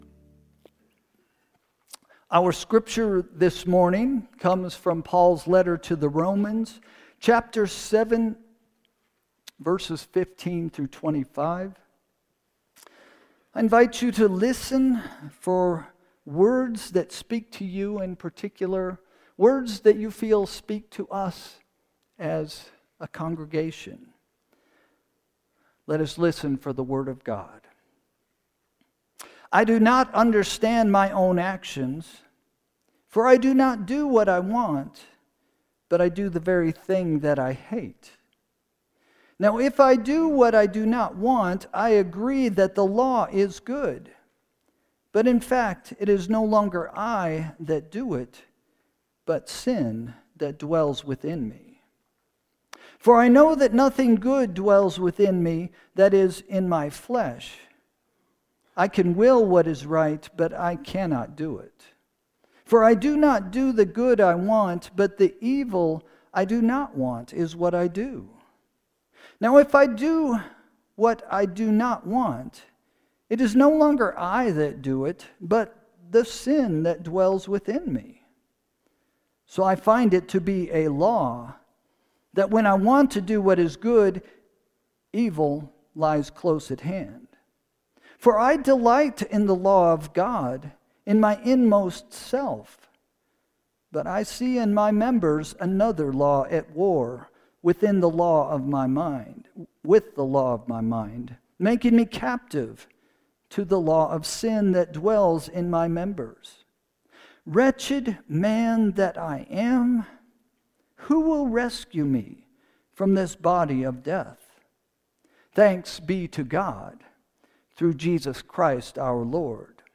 Sermon – July 6, 2025 – “Freedom From Sin” – First Christian Church